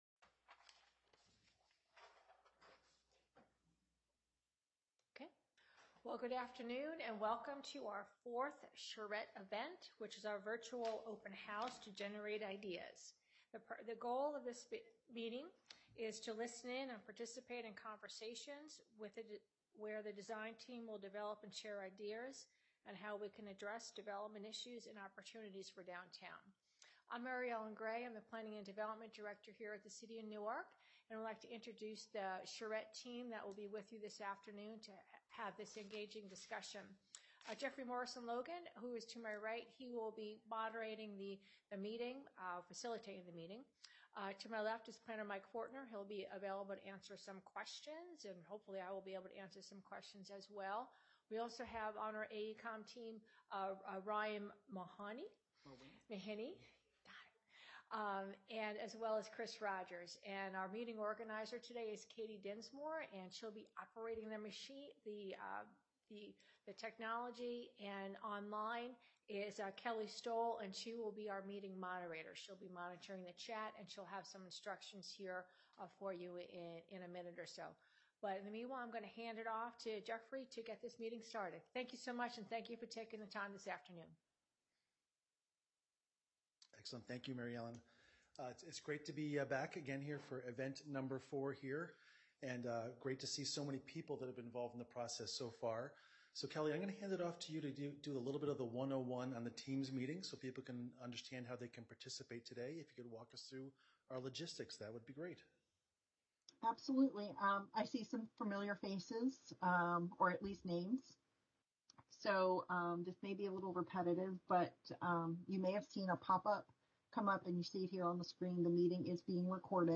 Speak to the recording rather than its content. Event 4: Virtual Open House: Generate Ideas – Listen in and participate in conversations where the design team will develop and share ideas on how we can address development issues and opportunities downtown.